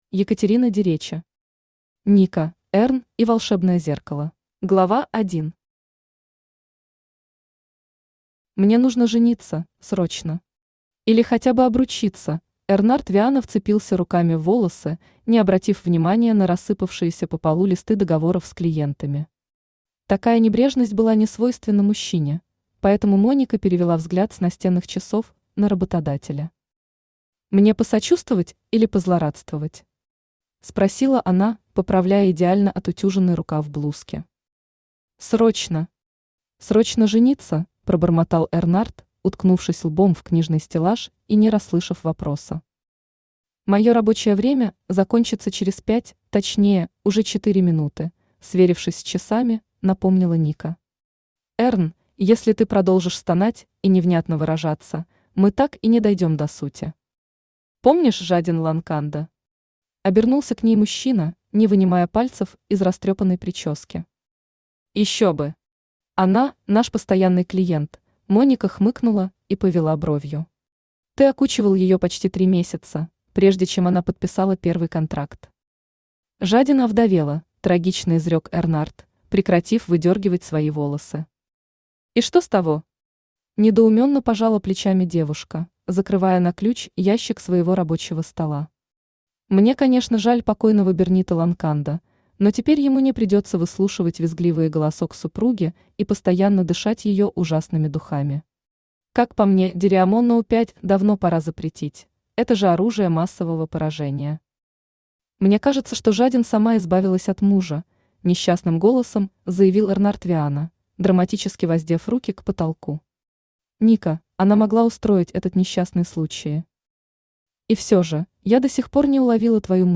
Аудиокнига Ника, Эрн и волшебное зеркало | Библиотека аудиокниг
Aудиокнига Ника, Эрн и волшебное зеркало Автор Екатерина Дереча Читает аудиокнигу Авточтец ЛитРес.